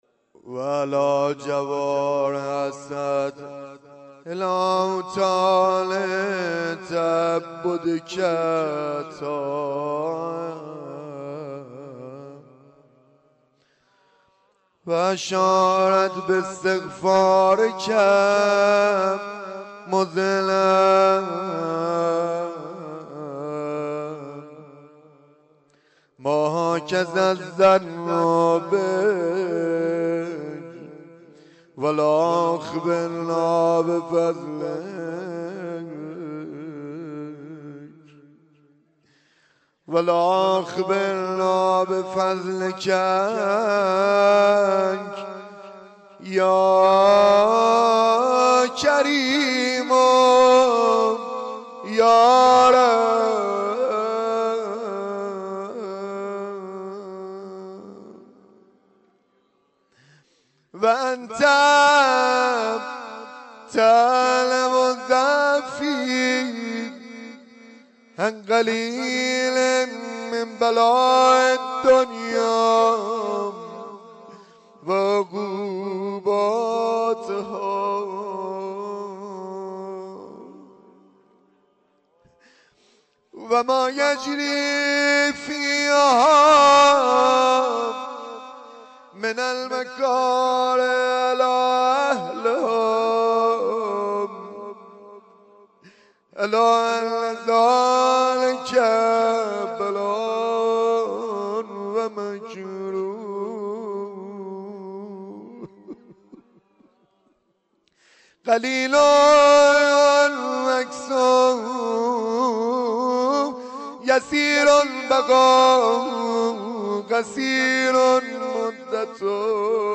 دعای کمیل حرم حضرت عبدالعظیم حسنی